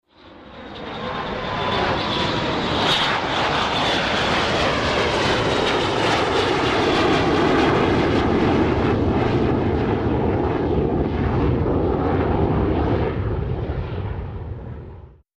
Two F-18 hornet jet fighters, low fly - by